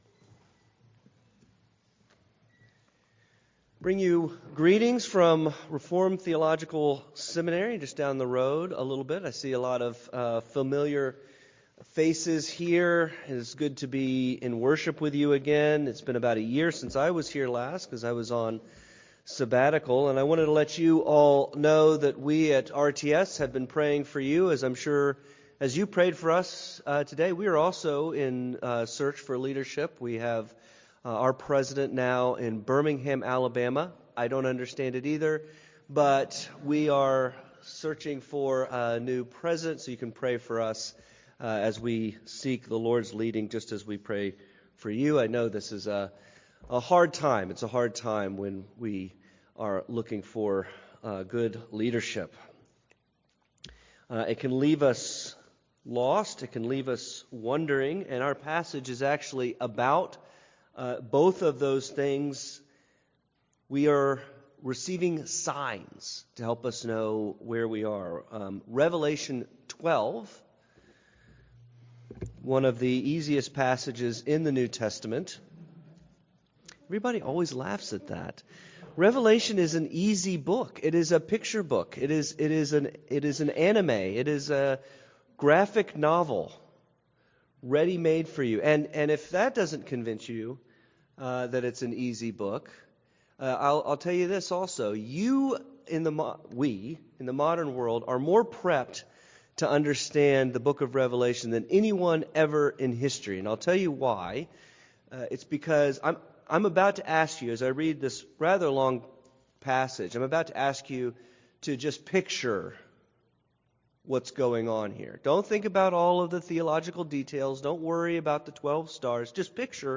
The Woman, The Dragon, and the Child: Sermon on Revelation 12 - New Hope Presbyterian Church
november-9-2025-sermon-only.mp3